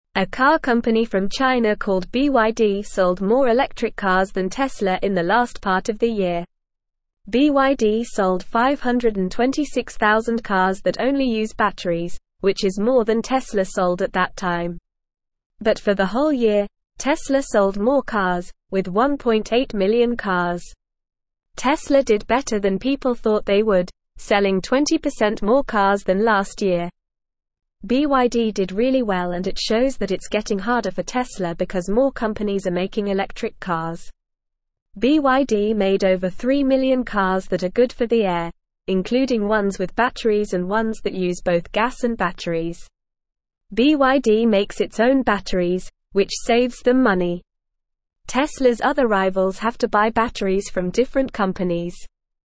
Normal
English-Newsroom-Beginner-NORMAL-Reading-BYD-sells-more-electric-cars-than-Tesla.mp3